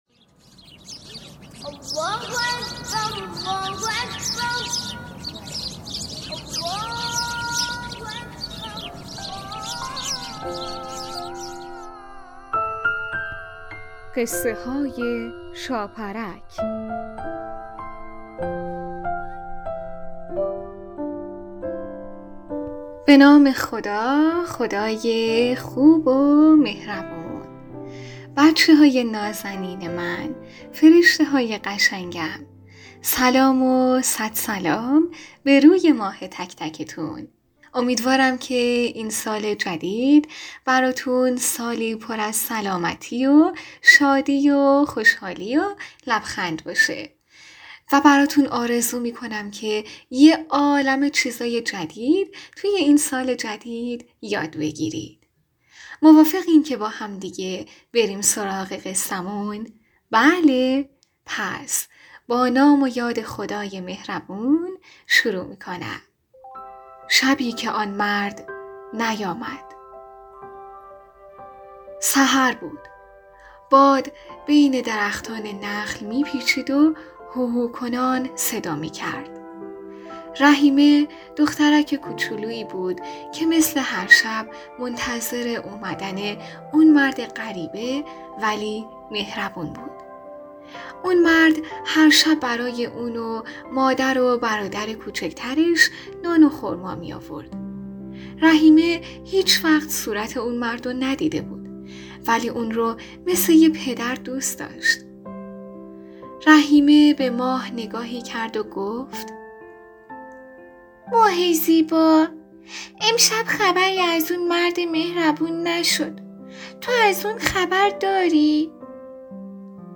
قسمت صد و بیست و دوم برنامه رادیویی قصه های شاپرک با نام روزه‌ی کله گنجشکی یک داستان کودکانه مذهبی با موضوعیت نماز